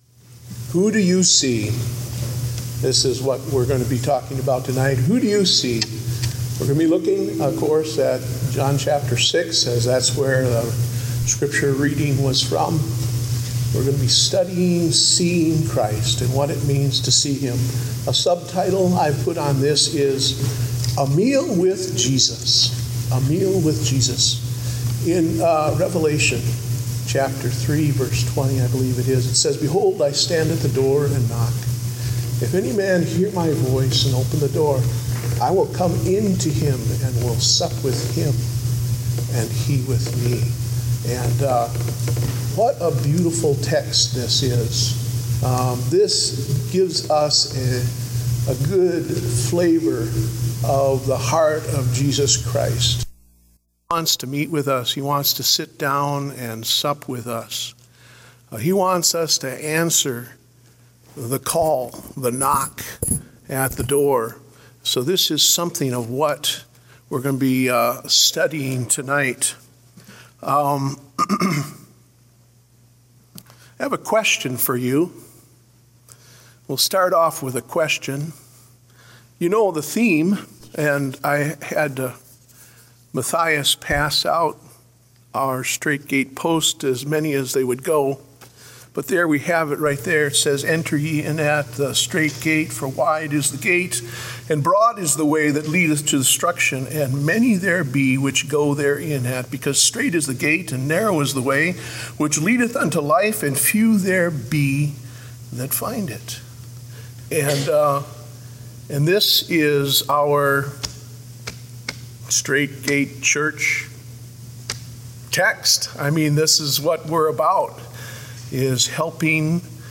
Date: May 22, 2016 (Evening Service)